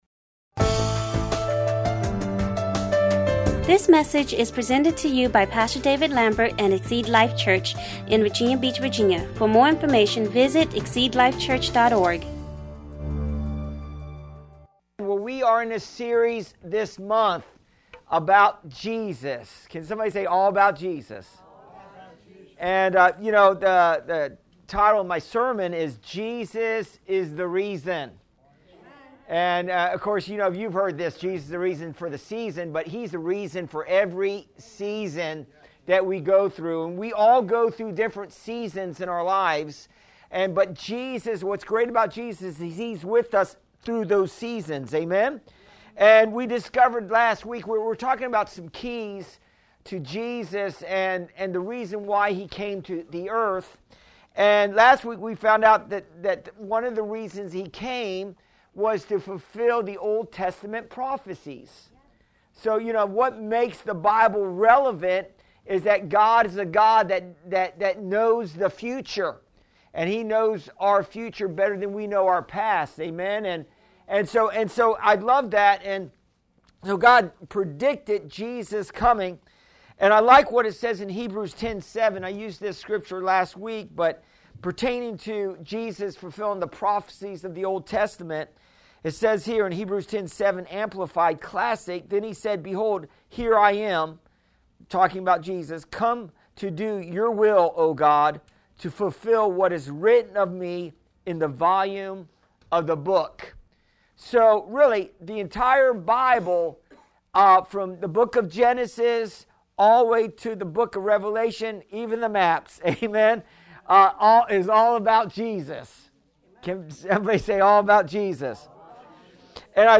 Sermons Archive 2019